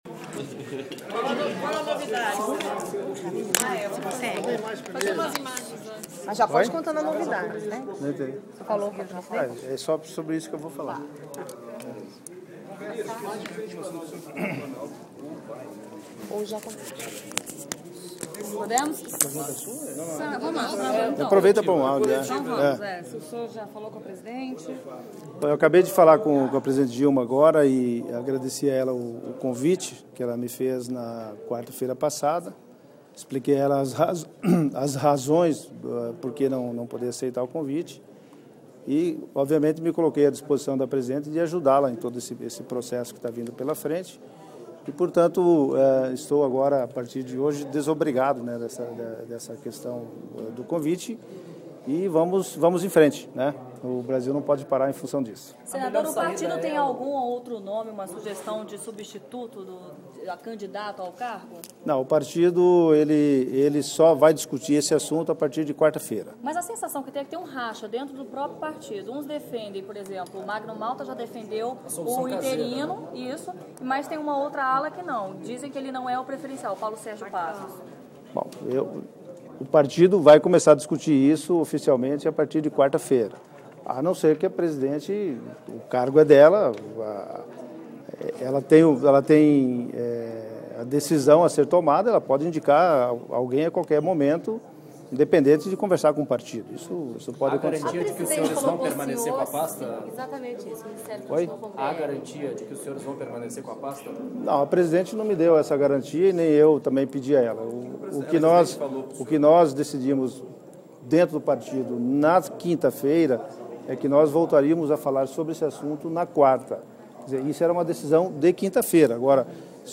O anúncio, feito por uma nota curta da Presidência da República, pegou o PR de surpresa, e ocorreu quase que simultaneamente a uma coletiva em que Blairo Maggi concedia à imprensa de Brasília para explicar os motivos da recusa ao cargo para o qual fora convidado pela presidente Dilma Roussef na semana passada.